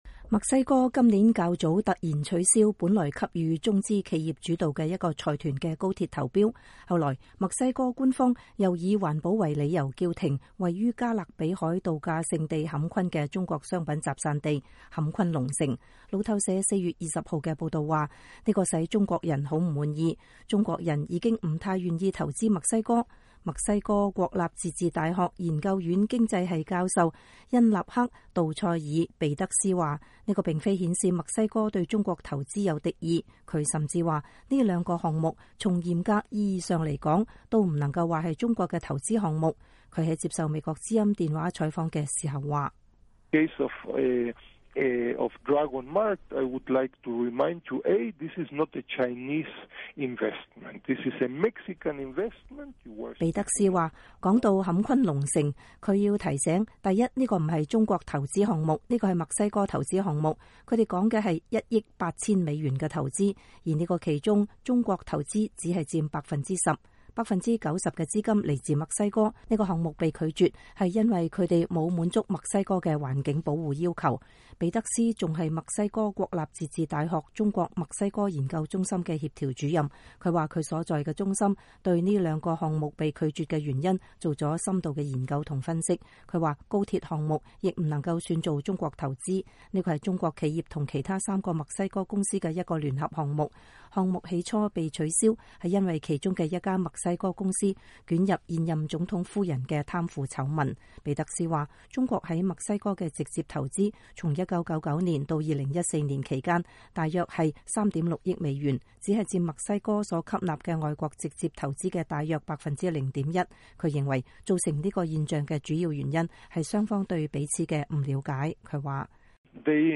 他在接受美國之音電話採訪時說： “說到'坎昆龍城' ，我得提醒你，第一，這不是中國投資項目，這是個墨西哥投資項目。我們說的是 1 億 8000 美元的投資，而這其中中國投資只佔 10% ， 90% 的資金來自墨西哥。這個項目被拒絕是因為他們沒有滿足墨西哥的環境保護要求。 ”